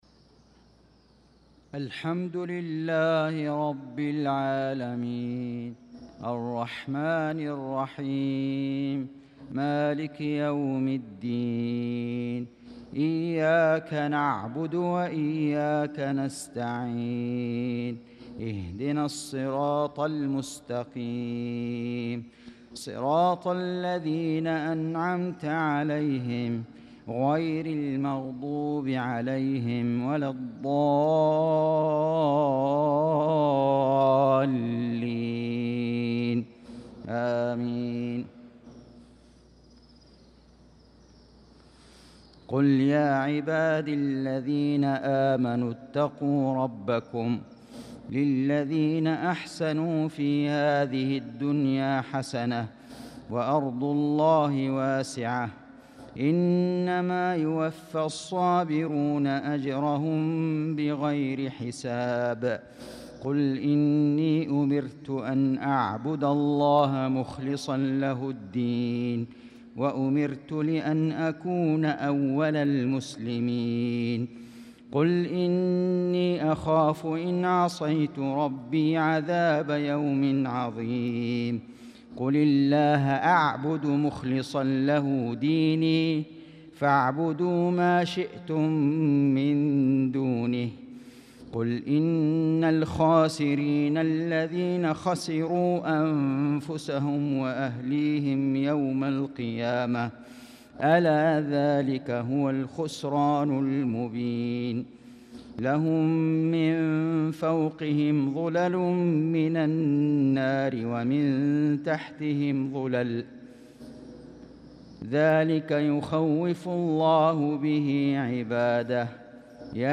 صلاة المغرب للقارئ فيصل غزاوي 24 شوال 1445 هـ
تِلَاوَات الْحَرَمَيْن .